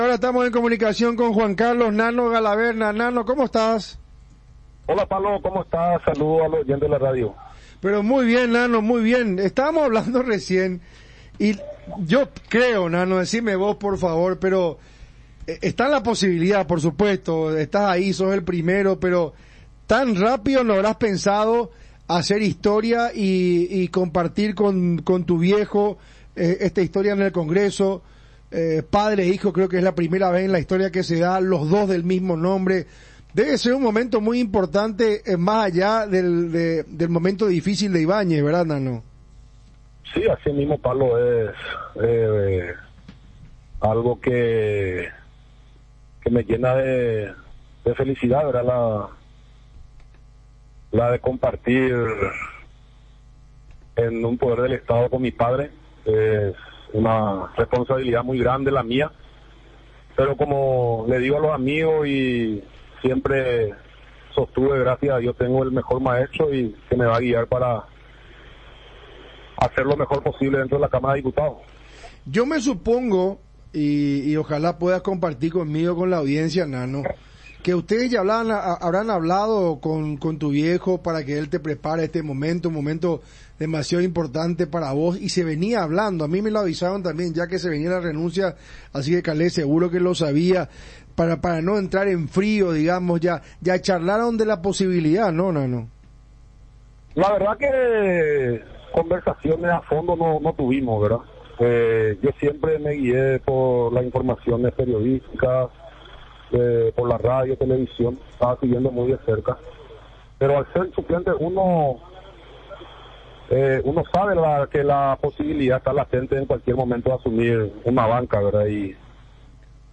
Galaverna manifestó su felicidad, en comunicación con el programa Zona Franca, de radio Ñandutí. Indicó que se siente confiado porque recibió una educación política idónea por parte de su padre.